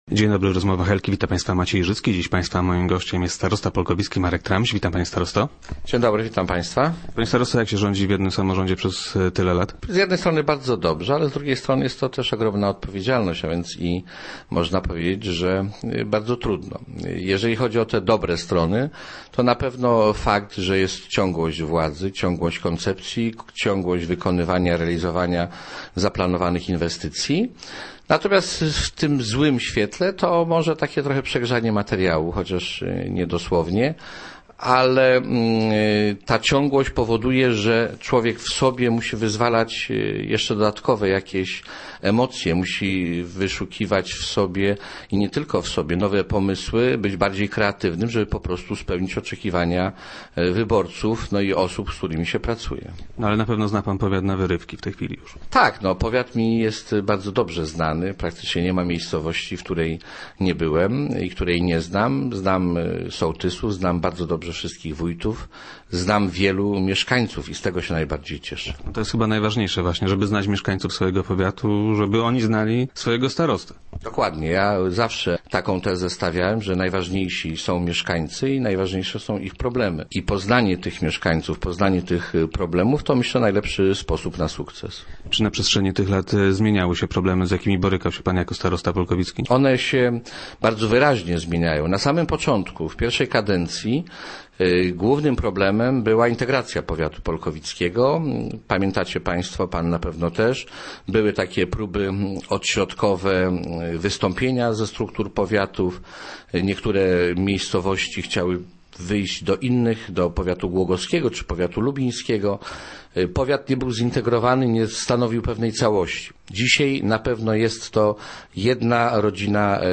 Dziś był gościem Rozmów Elki.